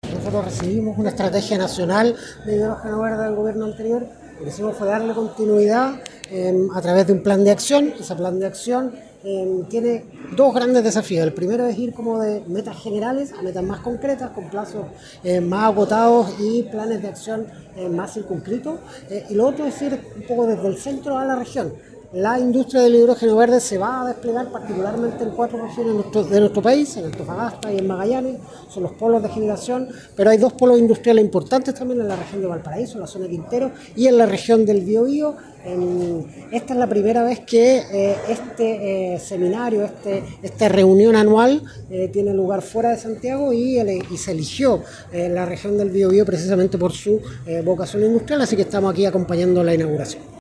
Este martes se realizó en Biobío el Green Hydrogen Summit Chile LAC 2025, instancia donde se abordaron las oportunidades y desafíos del desarrollo energético regional, por primera vez fuera de Santiago, considerando el rol estratégico de la industria local.